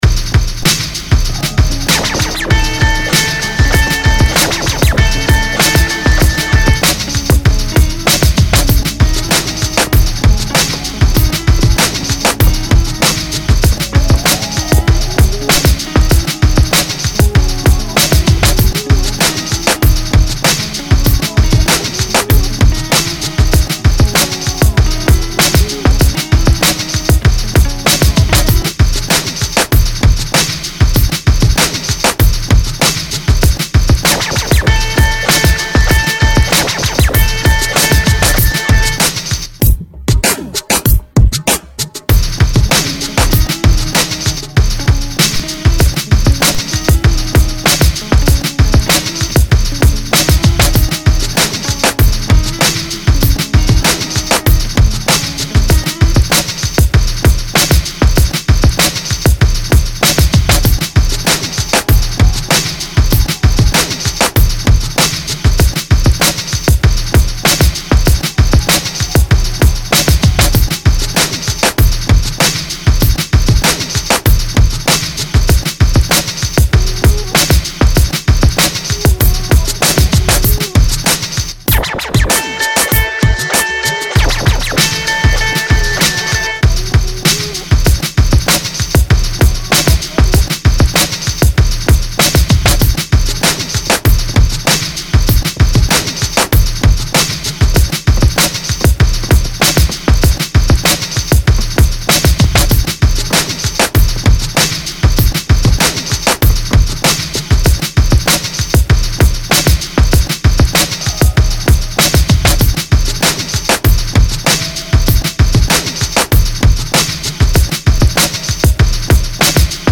Independencyradioinstrumental Mp 3